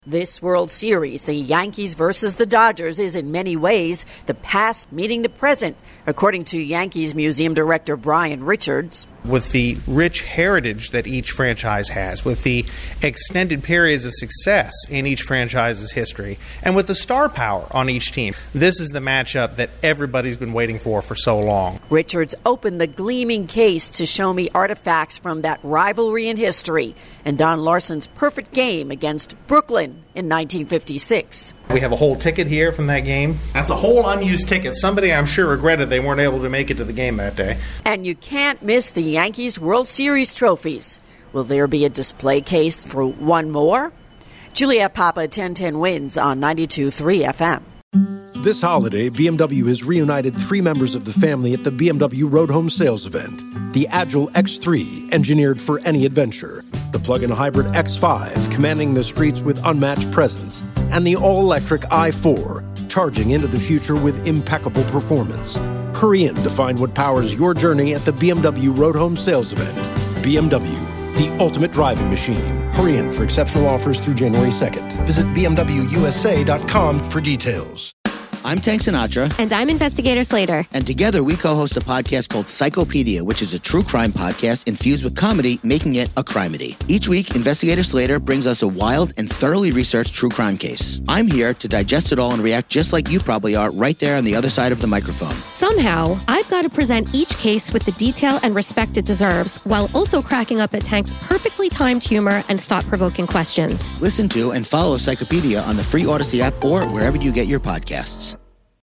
1010 WINS News Update